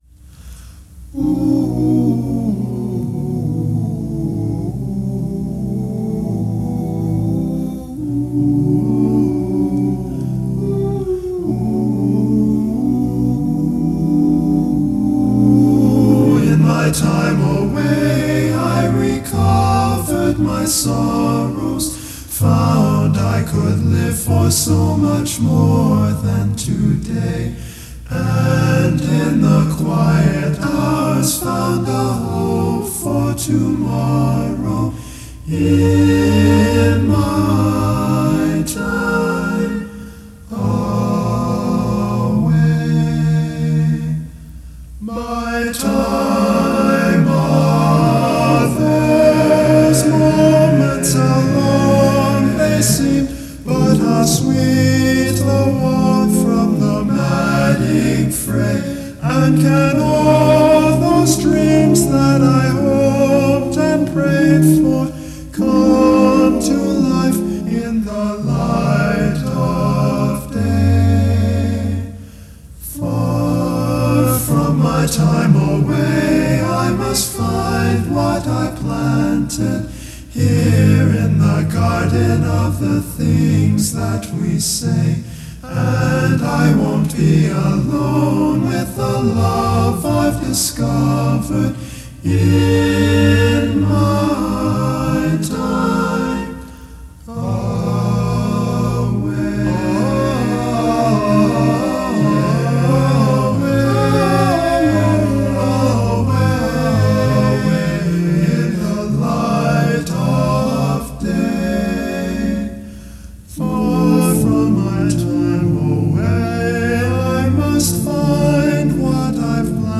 vocals / guitars / sequencing